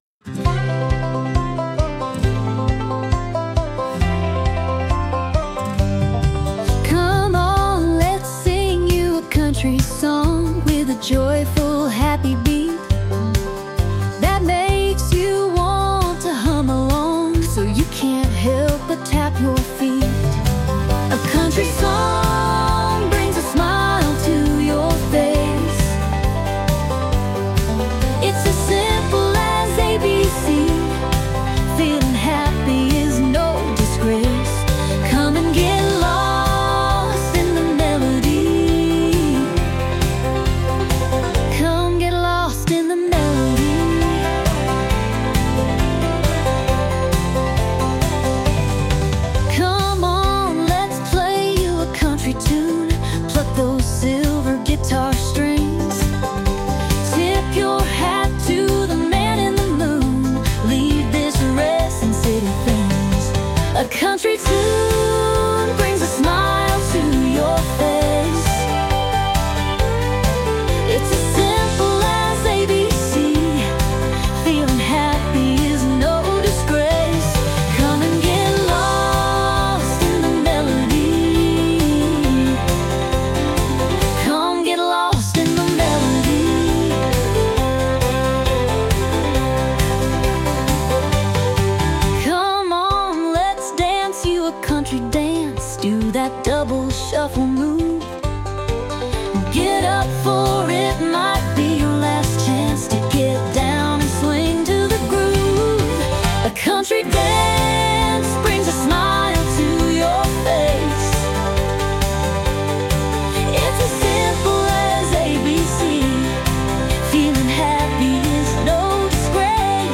female led Country album